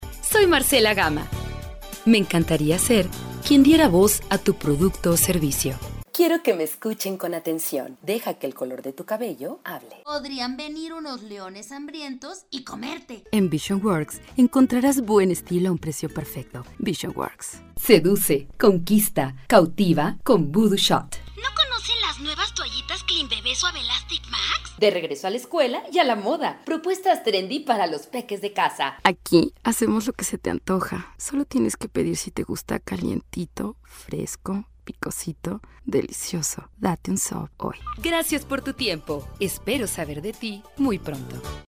Locutora mexicana profesional
Sprechprobe: Industrie (Muttersprache):
Professional mexican announcer with experience in radio, tv spots, corporate videos and social networks